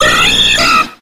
Cries
PERSIAN.ogg